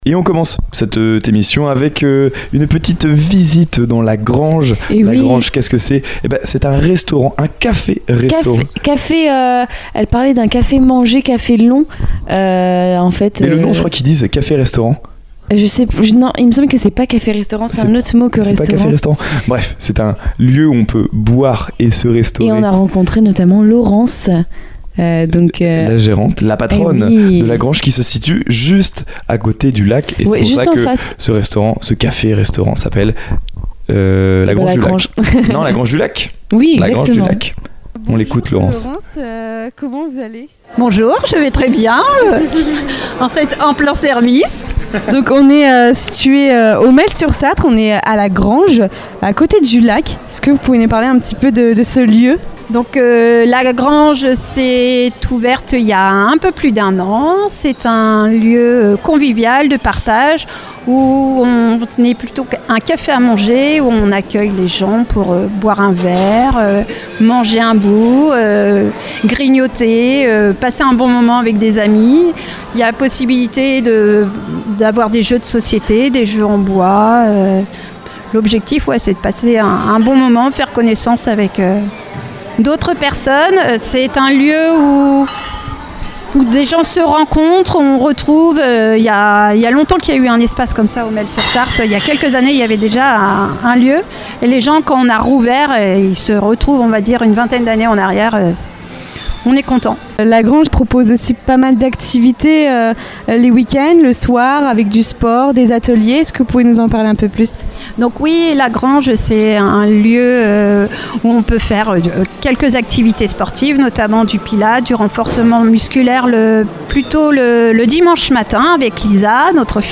Reportage - restaurant la grange du 30.07.2025
Dans cet épisode, la Mystery Machine fait escale au La Grange à Le Mêle-sur-Sarthe.
Un reportage humain et gourmand, entre transmission, convivialité et art de vivre local.